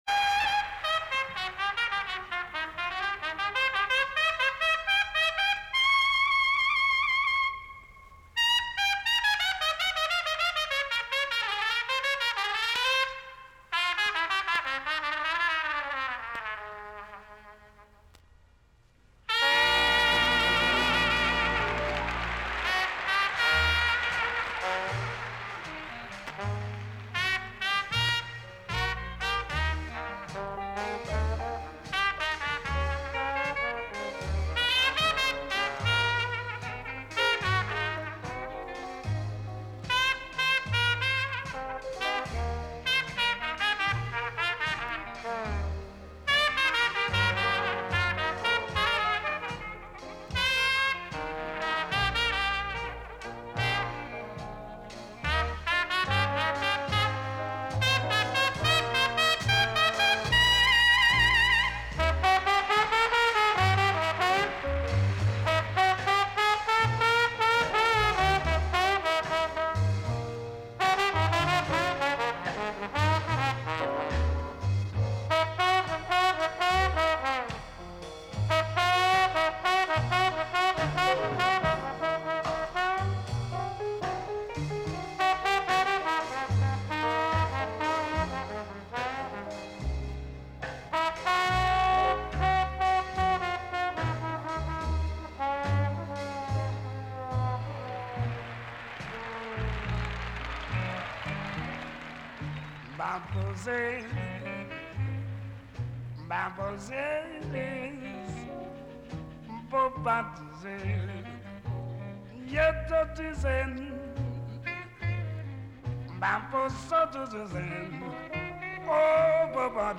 recorded live during a European tour